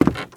High Quality Footsteps
STEPS Wood, Creaky, Walk 05.wav